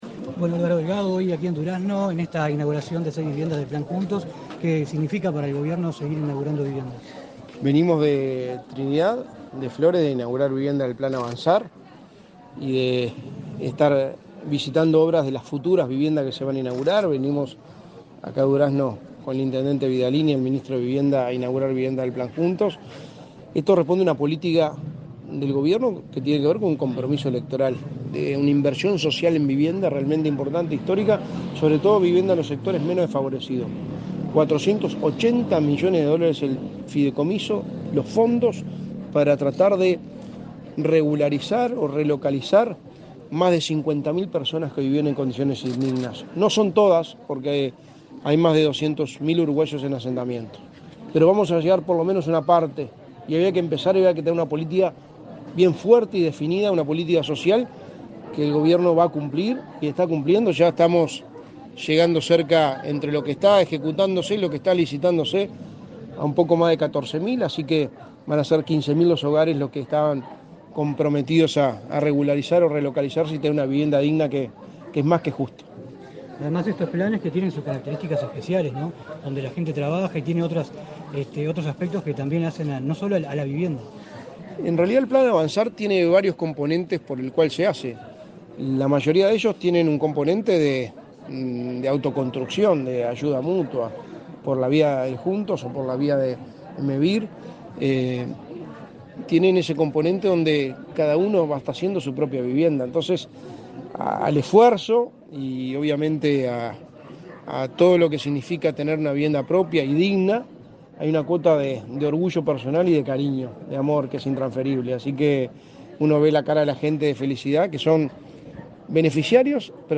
Declaraciones a la prensa del secretario de la Presidencia, Álvaro Delgado
Declaraciones a la prensa del secretario de la Presidencia, Álvaro Delgado 27/07/2023 Compartir Facebook X Copiar enlace WhatsApp LinkedIn El Ministerio de Vivienda y Ordenamiento Territorial entregó este 27 de julio, a través del plan Juntos, seis viviendas en el barrio Las Higueras, en la ciudad de Durazno. Tras el evento, el secretario de la Presidencia, Álvaro Delgado, realizó declaraciones a la prensa.